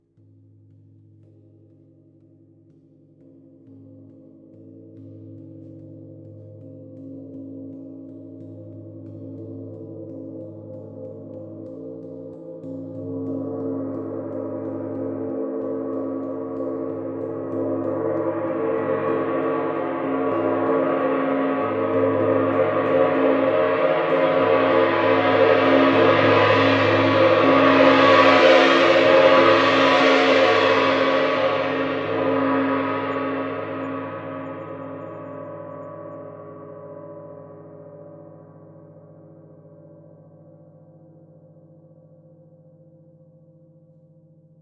Gong Strike Samples » entrance gong
描述：A loud gong strike suitable for a grand entrance
标签： Grand GongStrike Enrance Sample
声道立体声